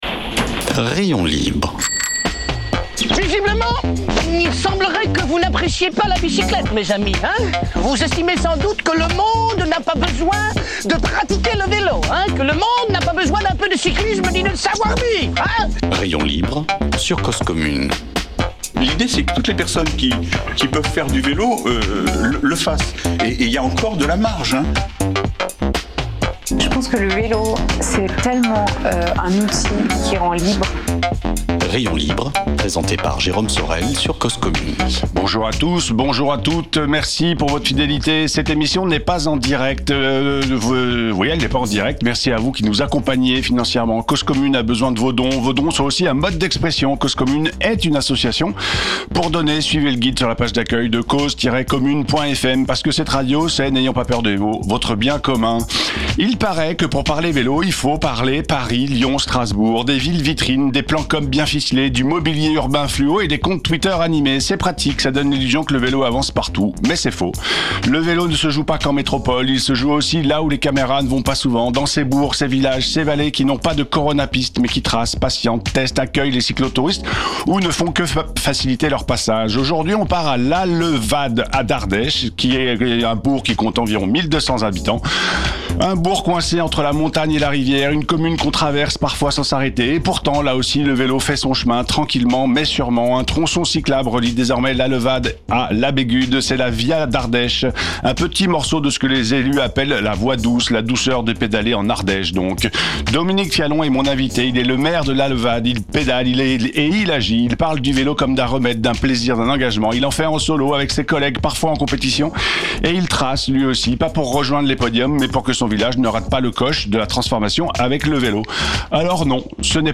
En plateau Dominique Fialon Dominique Fialon est le maire de Lalevade. Une commune d'un peu plus de 1000 habitants